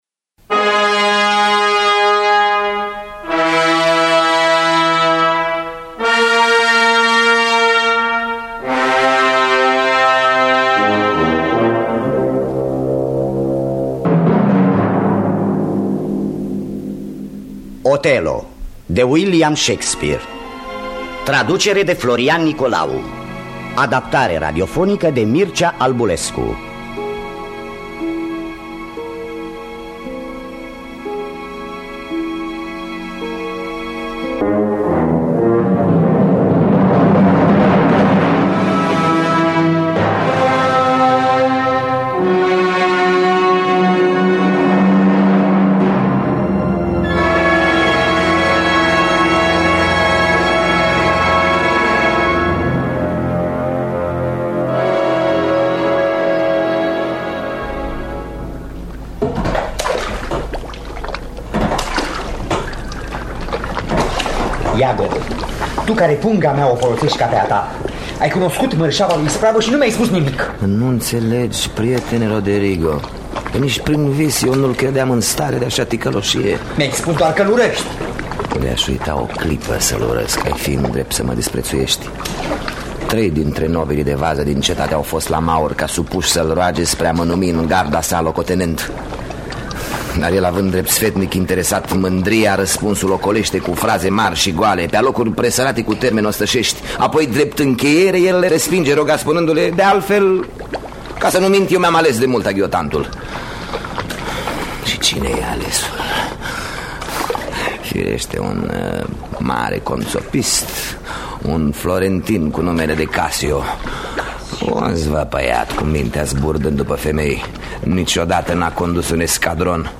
Adaptarea radiofonică de Mircea Albulescu.